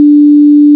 1 channel
b3engine.mp3